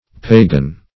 Pagan \Pa"gan\ (p[=a]"gan), n. [L. paganus a countryman,